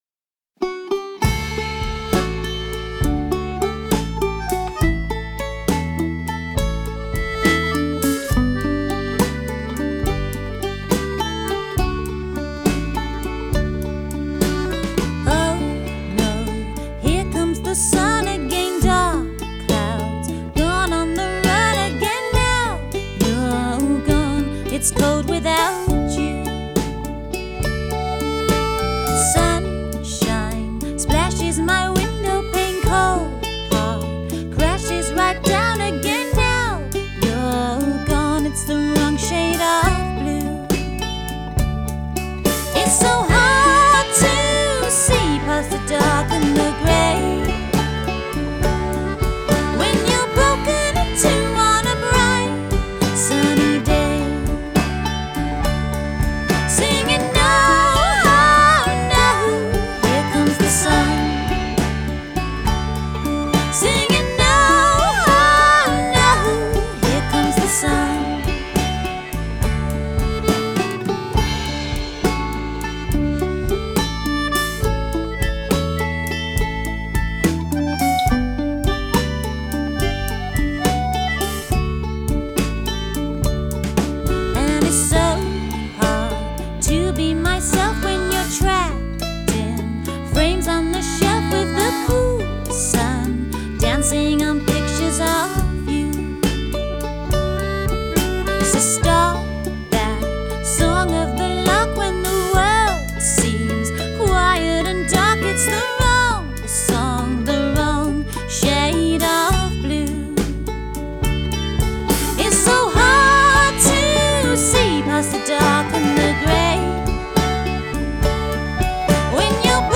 Genre: Folk